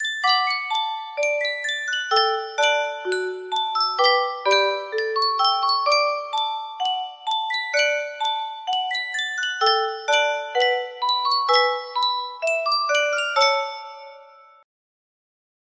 Sankyo Music Box - Bedelia GHT music box melody
Full range 60